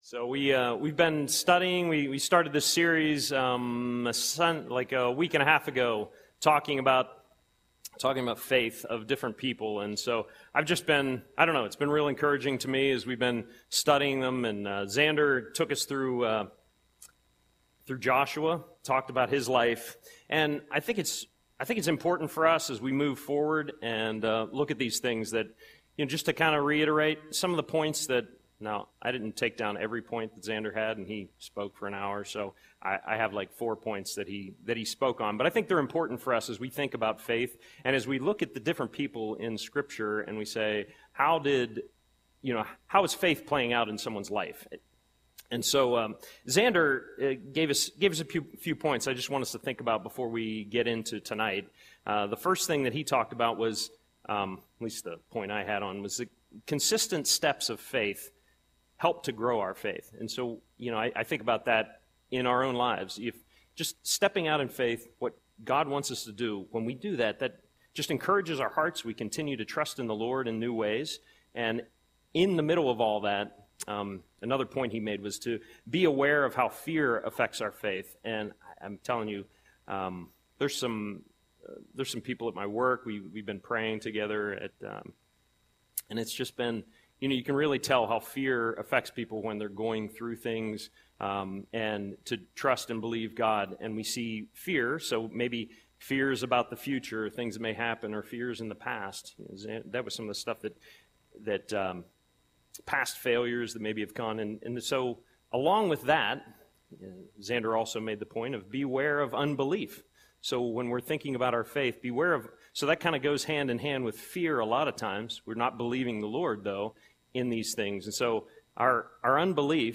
Audio Sermon - March 26, 2025